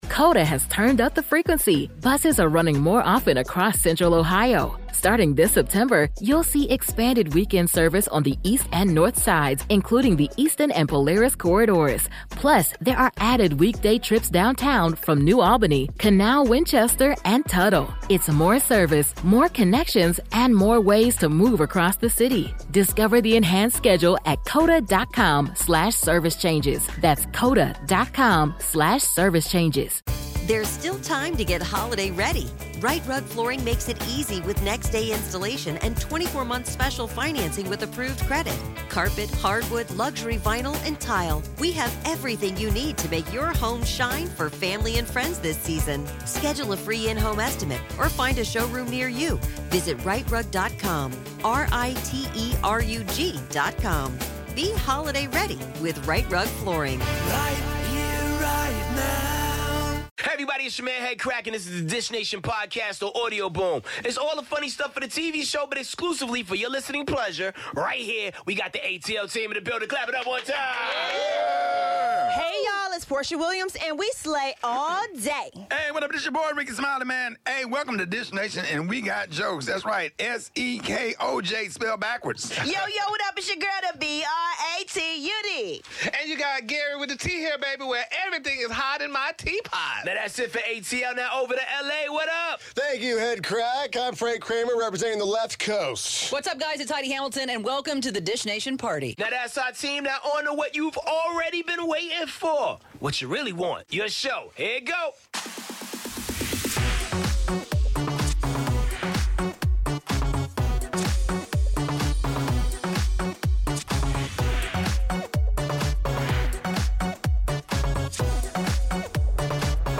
Guest co-host: Ms. Pat join us and the best moments from last night's 'Real Housewives of Atlanta.' Plus all the latest on Oprah, Blake Shelton, Kanye West, Kim Kardashian, Jennifer Lawrence, Safaree Samuels and much more!